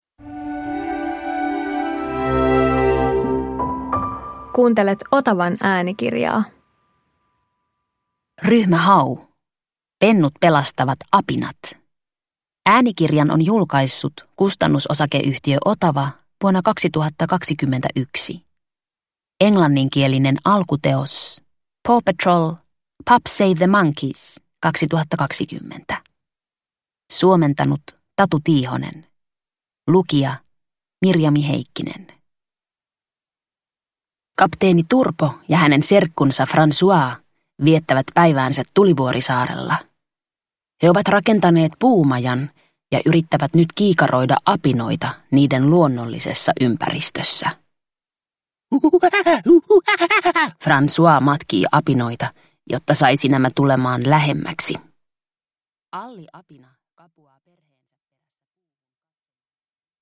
Ryhmä Hau - Pennut pelastavat apinat – Ljudbok – Laddas ner